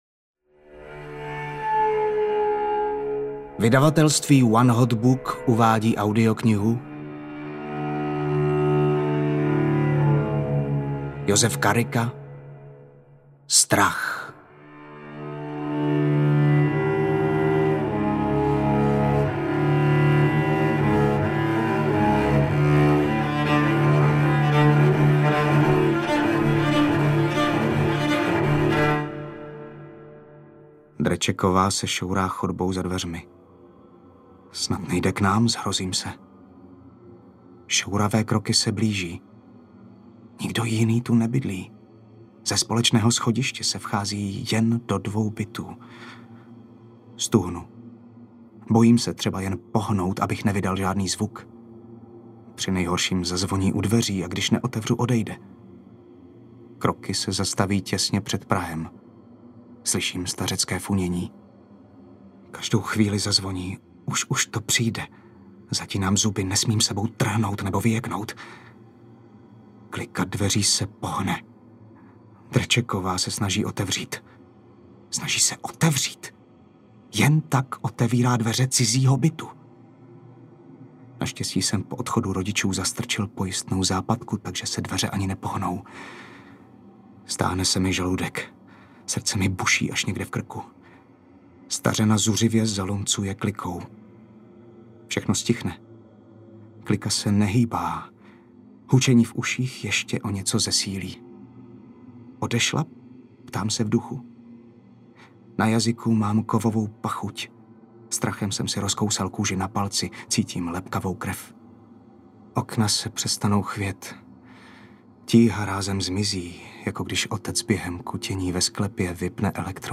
Strach audiokniha
Ukázka z knihy